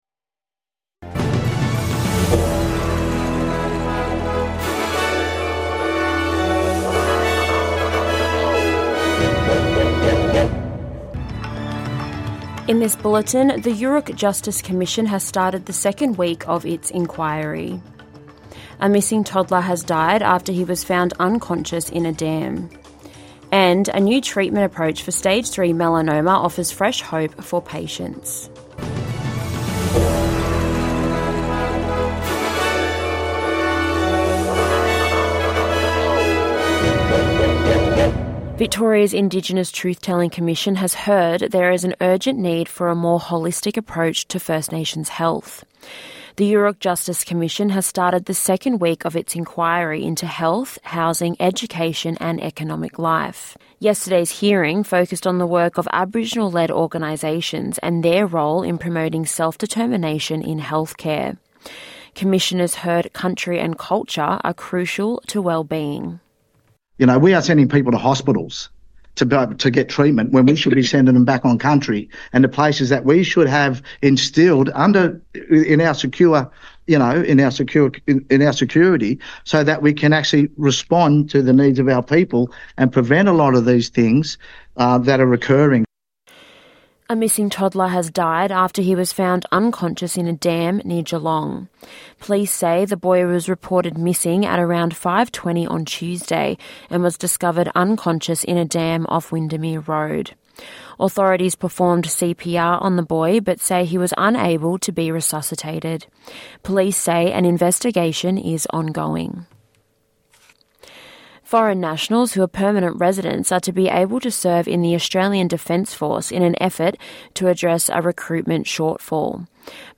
NITV Radio - News 5/06/2024